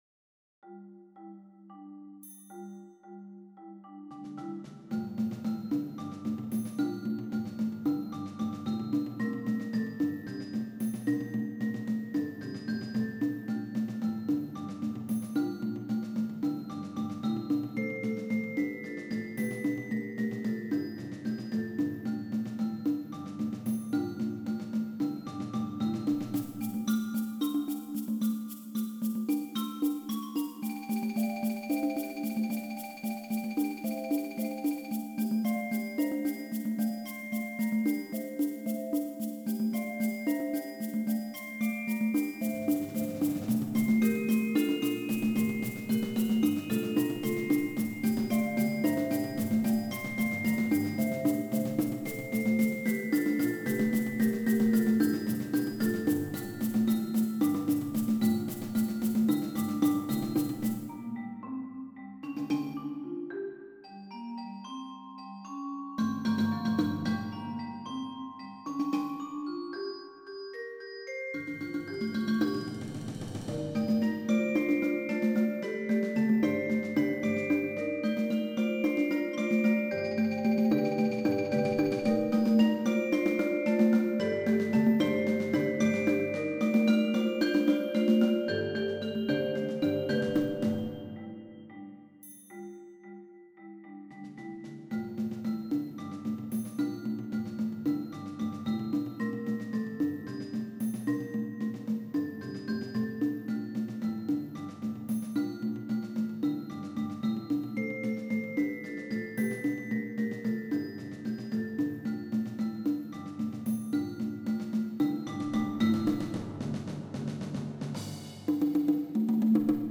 Percussion Ensemble